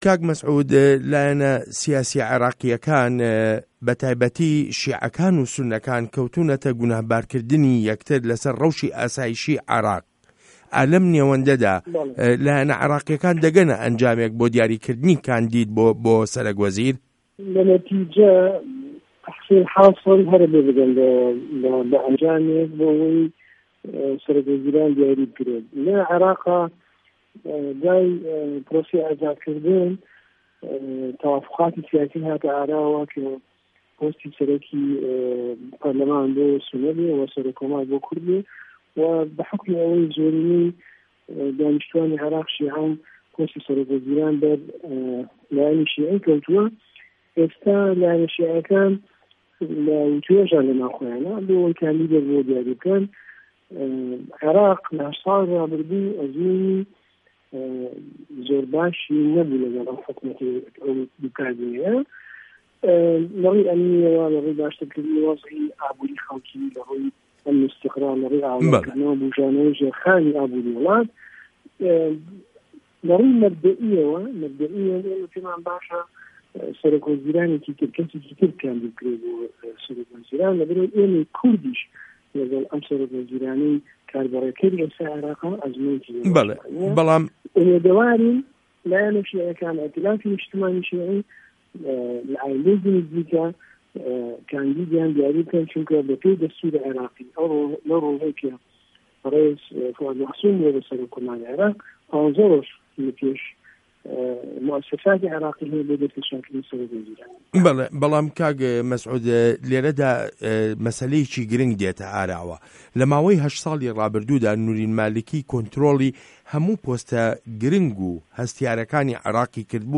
وتووێژ له‌گه‌ڵ مه‌سعود حه‌یده‌ر